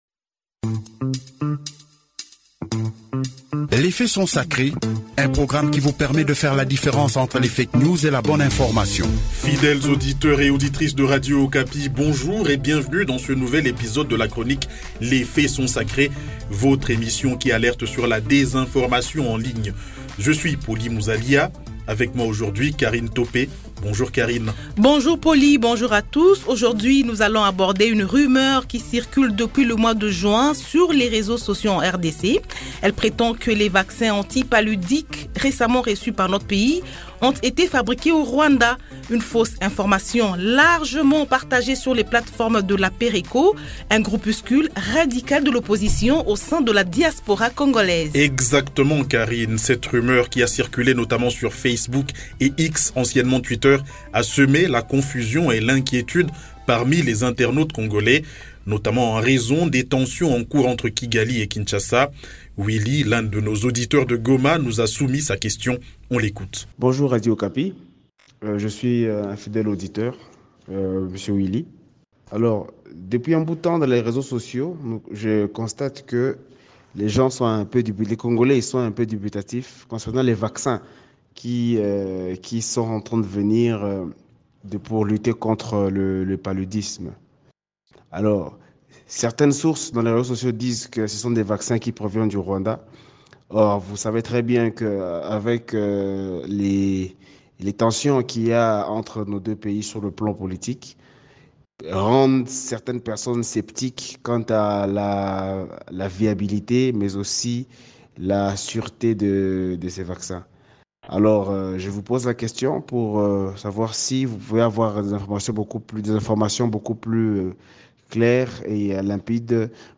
Ces vaccins ont en réalité été fabriqués en Inde, comme le précise le ministre congolais de la Santé, le docteur Roger Kamba, qui s'exprime dans cette émission.